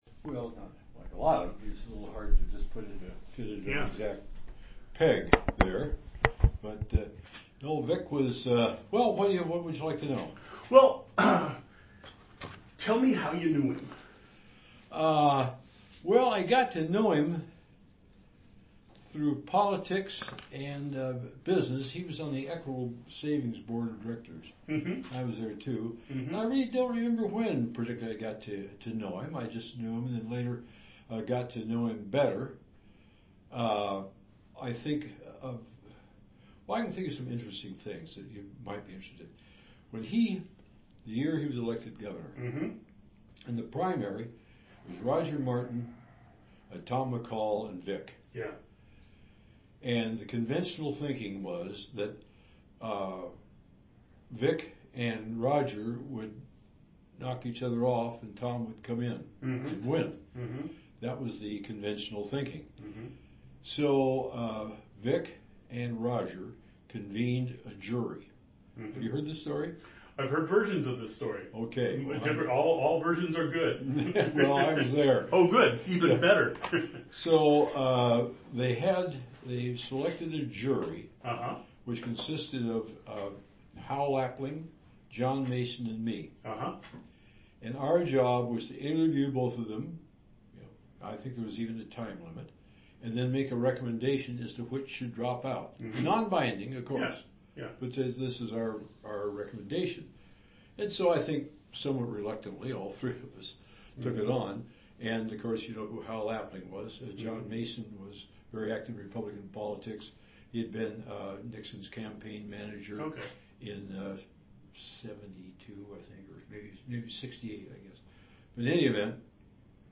d1e2b0b629ca7e9f9492cc577d32c0e487d1e585.mp3 Title Jack Faust interview on Atiyeh Description An interview of Jack Faust on the topic of Oregon Governor Vic Atiyeh, recorded on April 15, 2015.